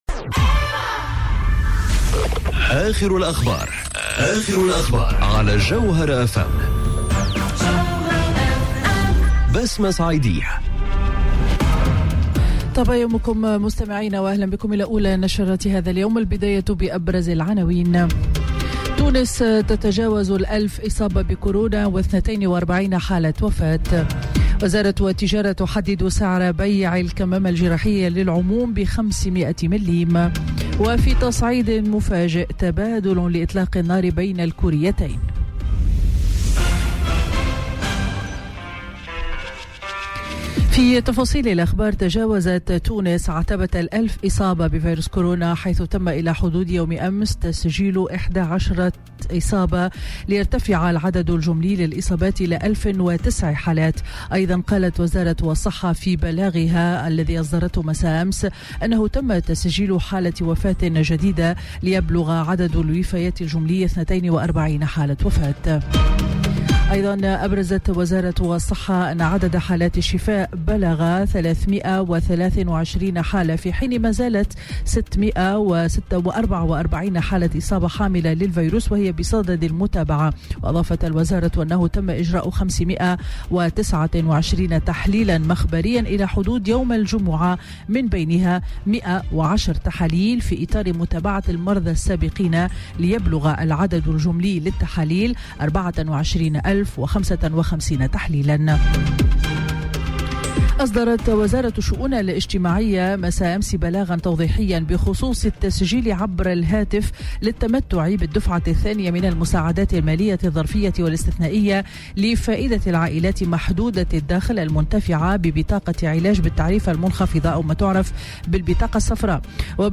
نشرة أخبار السابعة صباحا ليوم الأحد 03 ماي 2020